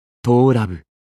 文件 文件历史 文件用途 全域文件用途 巴形登陆（加载中）.mp3 （MP3音频文件，总共长1.1秒，码率64 kbps，文件大小：9 KB） 巴形登陆（加载中）语音 文件历史 点击某个日期/时间查看对应时刻的文件。